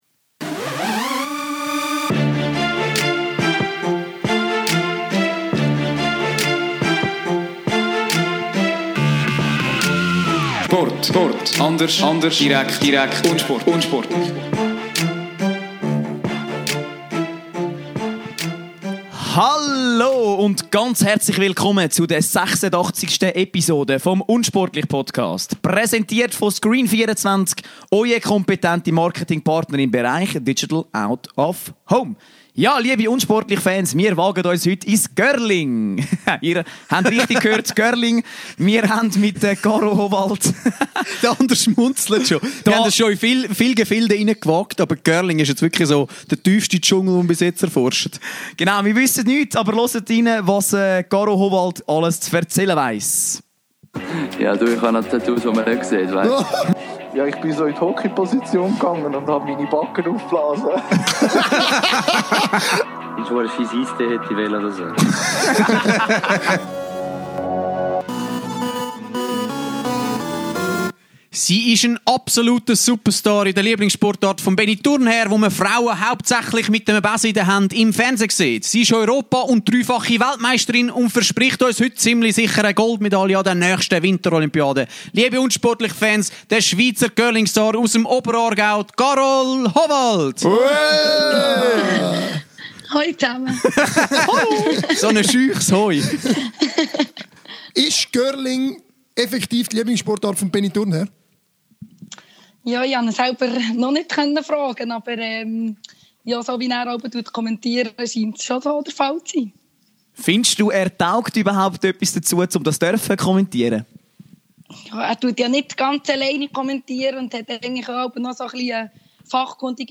Ein Interview ganz unter dem Motto: Eine Frau mit Besen steigert die unsportlich-Frauenquote! Scherz 😉 Wir lassen uns die Sportart Curling erklären, erfahren den Grund, weshalb es keine Schweizer Nati gibt, wettern über den auch im Curling allgegenwärtigen Schwedenfluch und beantworten drängende Fragen des Internets.